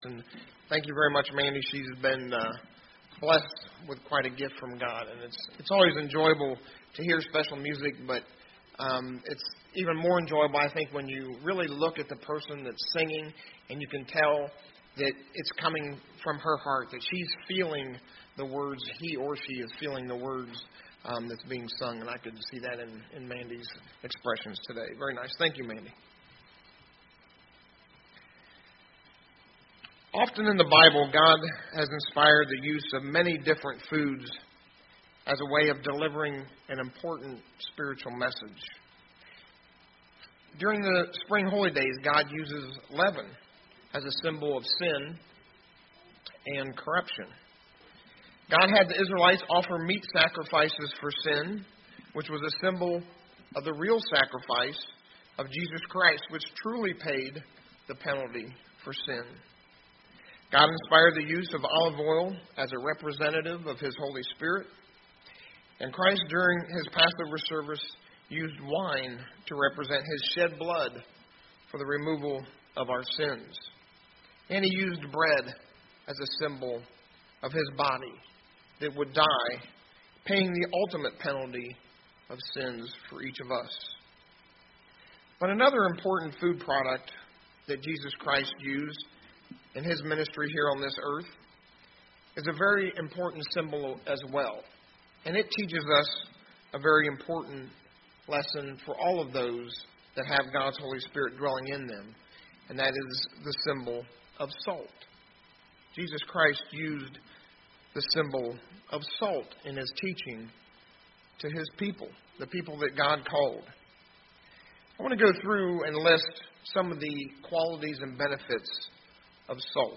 UCG Sermon Notes Notes: God has used olive oil, bread, wine as symbols for us.